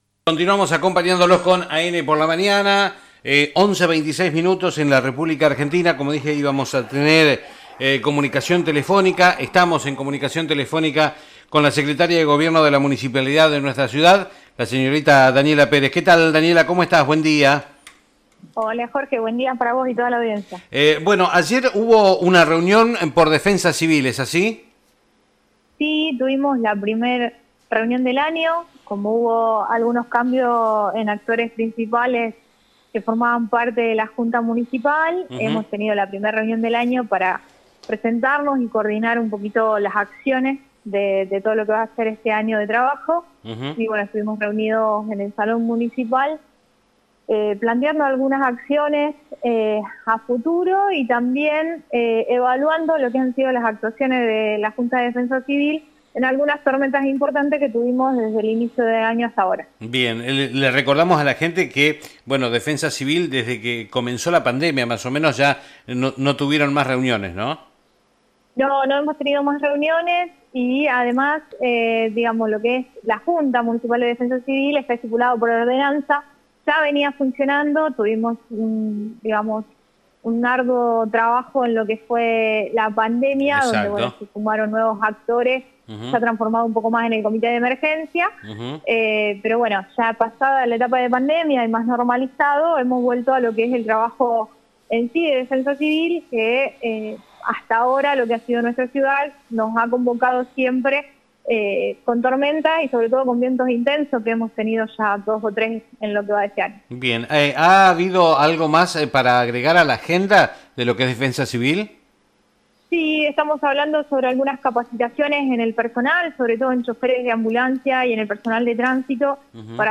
En horas de la mañana tuvimos una comunicación telefónica con la Secretaria de Gobierno Municipal, la señorita Daniela Pérez, quien nos brindo detalles sobre la reunión que se llevó a cabo con defensa Civil de la ciudad de Armstrong.